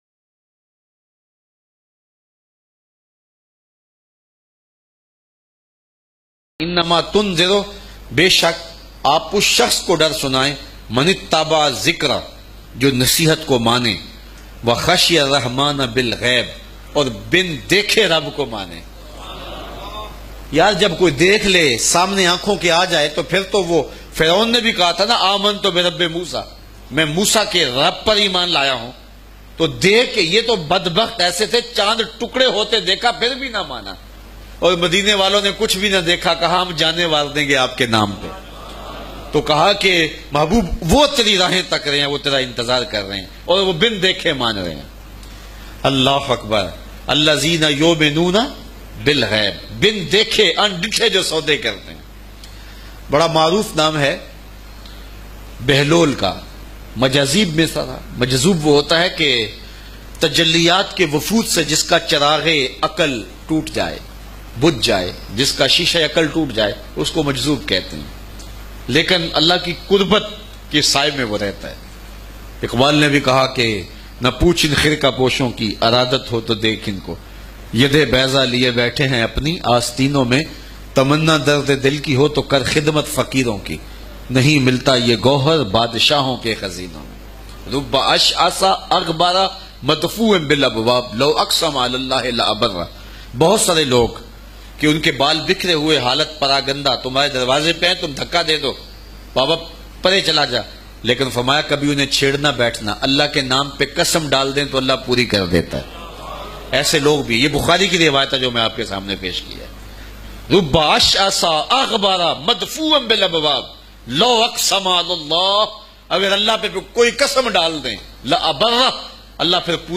bayan mp3 play online & download.